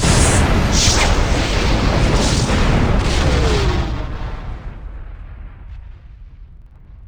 warp.wav